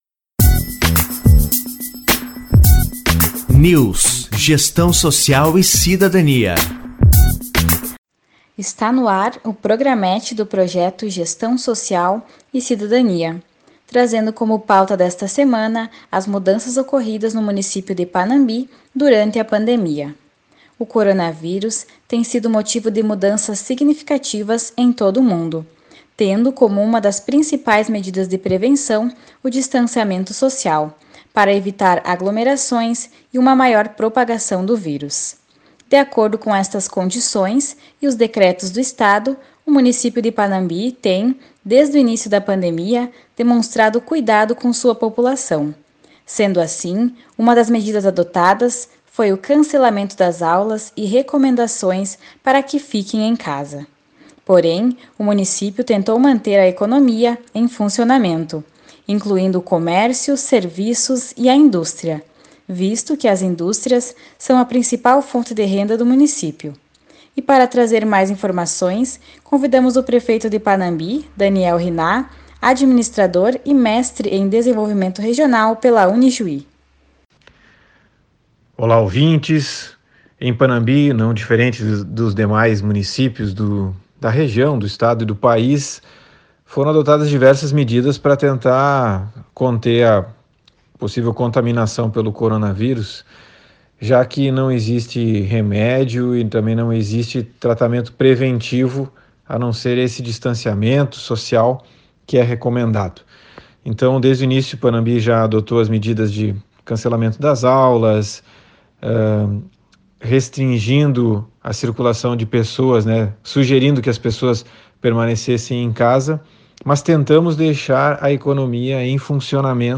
Entrevistado: Administrador, Mestre em Desenvolvimento Regional pela Unijuí e Prefeito de Panambi, Daniel Hinnah.